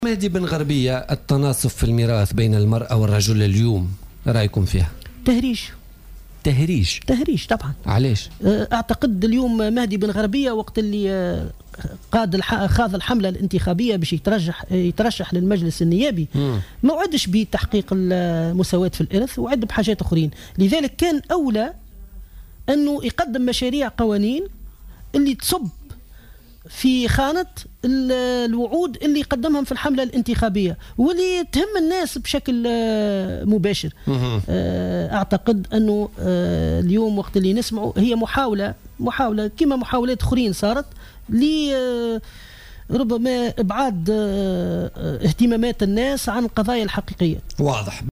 وقال لـ "الجوهرة أف أم" في برنامج "بوليتيكا" اليوم الأربعاء، كان يفترض ان يتمسّك النائب بن غربية بمشاريع أعلنها بمناسبة حملته الانتخابية، ولم يكن مشروع المساواة في الميراث ضمن مشاريع وعد بها ضمن حملته الانتخابية.